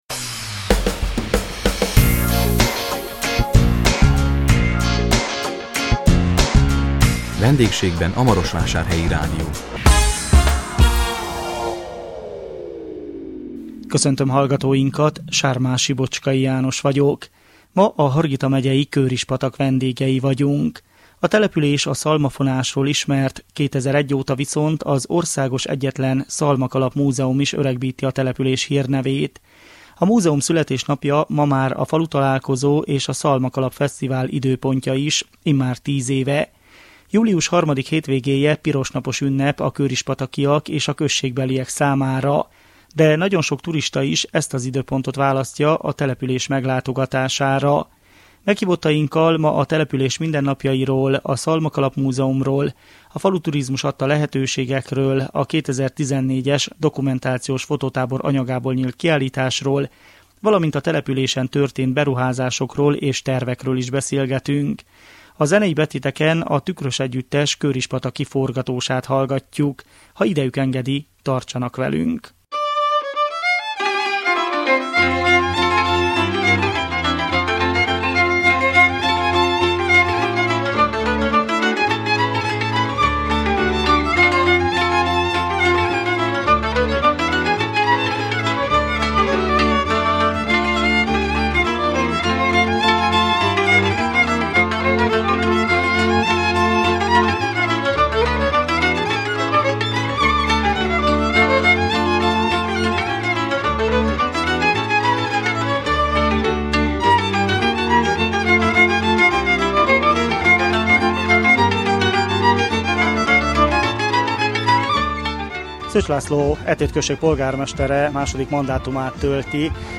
Ma a Hargita megyei Kőrispatak vendégei voltunk. Meghívottainkkal a beruházásokról és tervekről, a Szalmakalap Múzeumról, a falusi turizmus nyújtotta lehetőségekről és a település jövőjéről beszélgettünk.